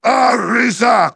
synthetic-wakewords
ovos-tts-plugin-deepponies_Rainbow Dash_en.wav